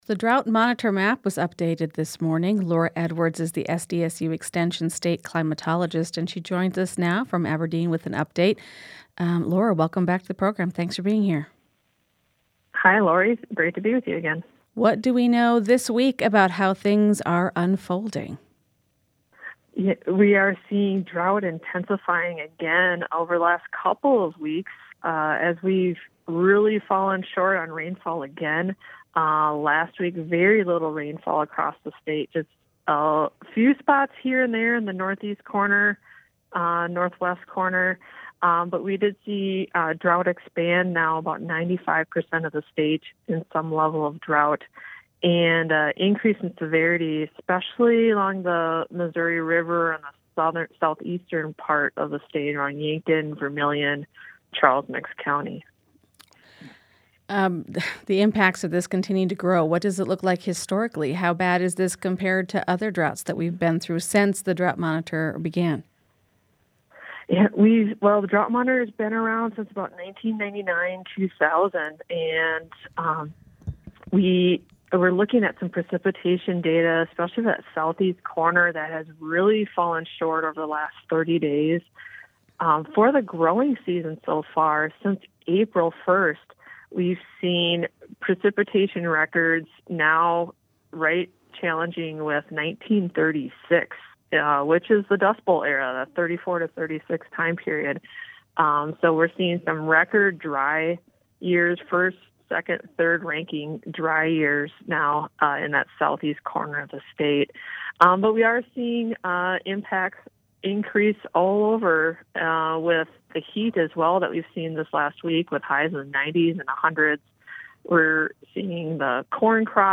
In the Moment is SDPB’s daily news and culture magazine program.